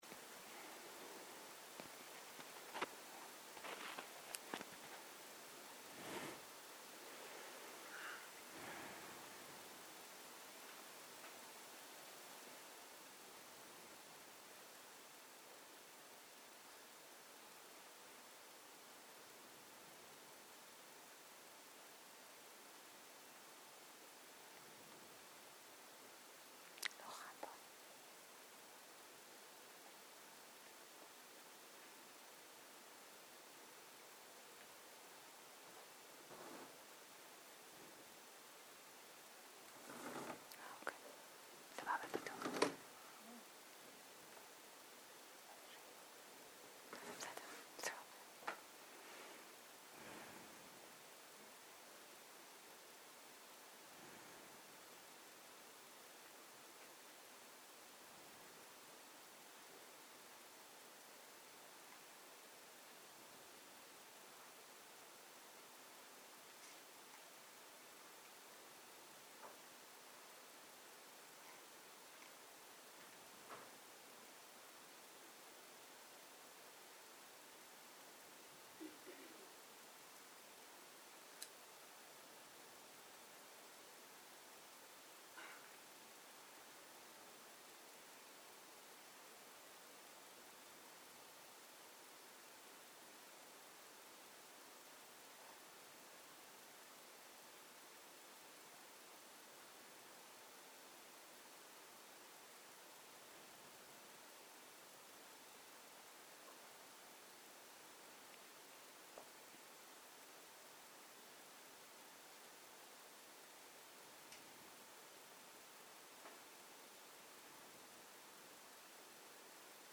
שיחת דהרמה
Dharma type: Dharma Talks שפת ההקלטה